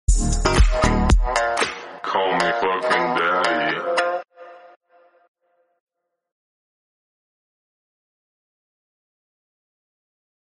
• Качество: 64, Stereo
мужской голос
deep house
EDM